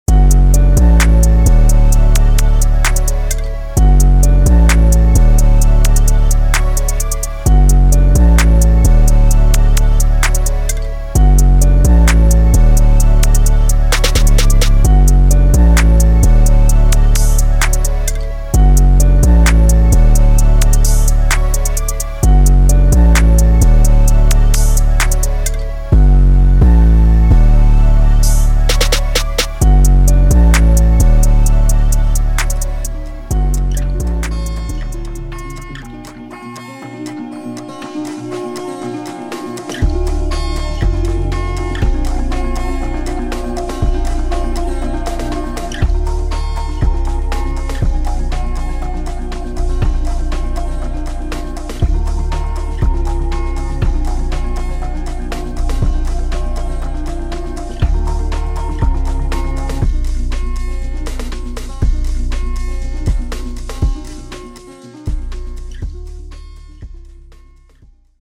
Trap
这个音色包包含旋律单音和循环、打击乐循环和鼓组单音。